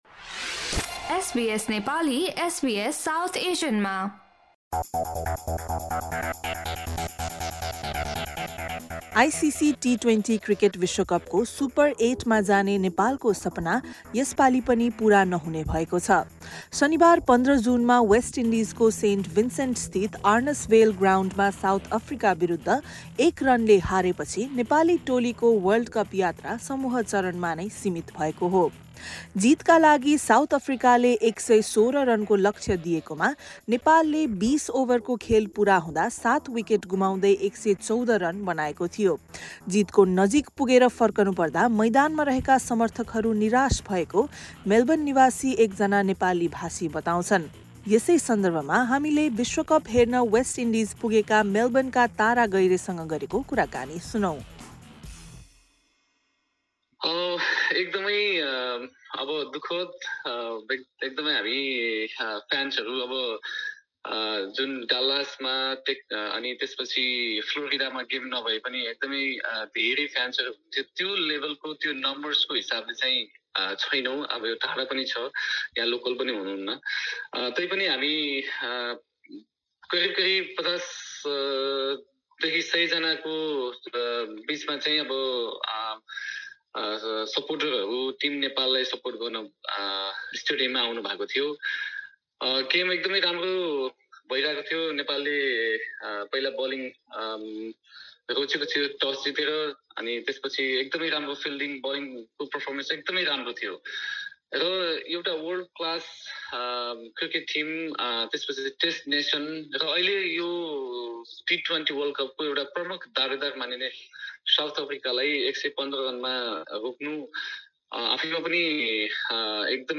एसबीएस नेपालीसँग गरेको कुराकानी सुन्नुहोस्।